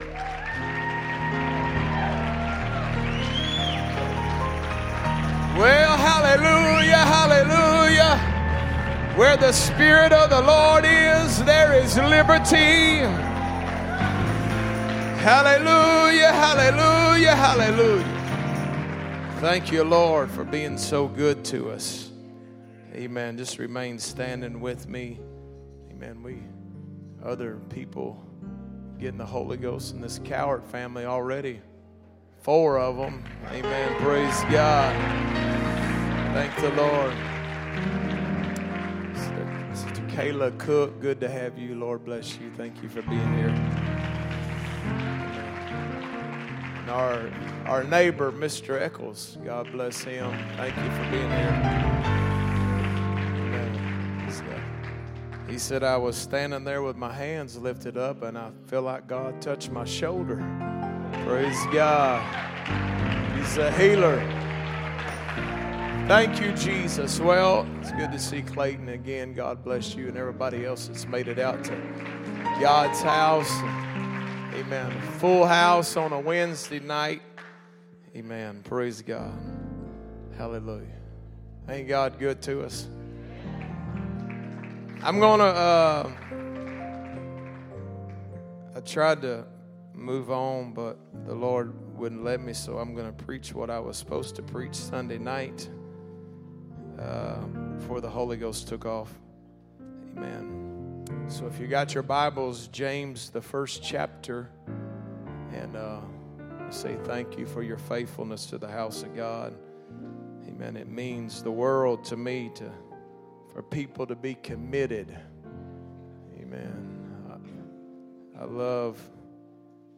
Sunday Night